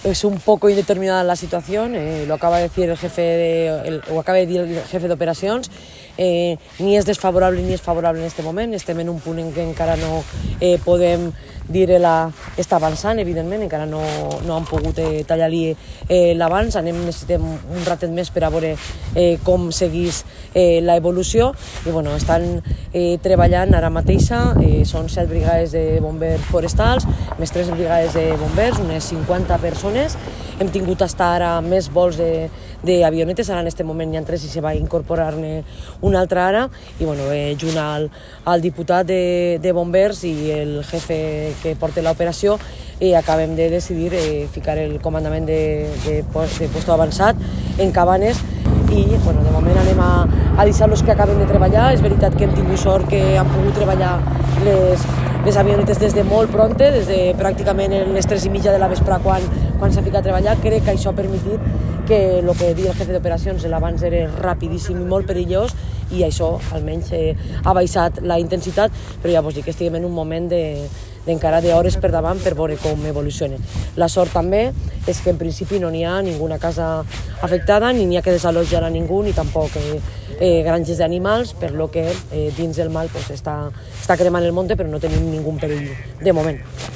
Presidenta-Marta-Barrachina-Incendio-Cabanes.mp3